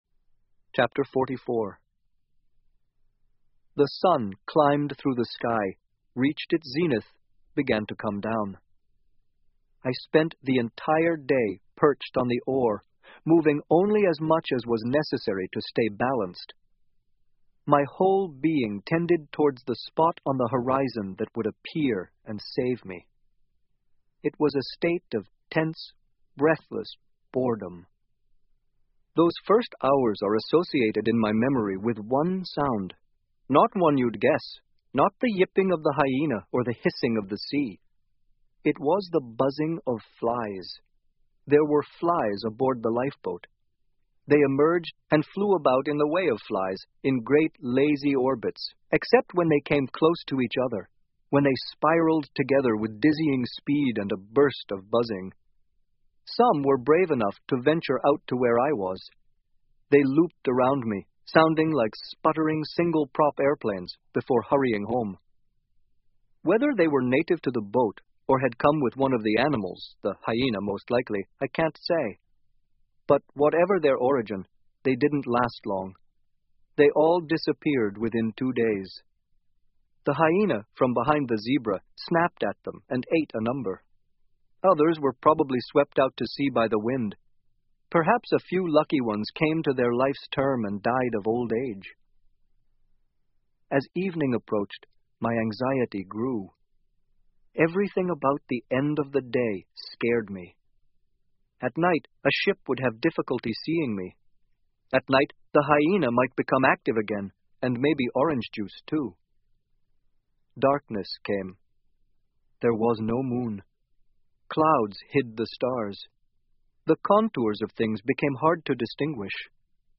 英文广播剧在线听 Life Of Pi 少年Pi的奇幻漂流 04-03 听力文件下载—在线英语听力室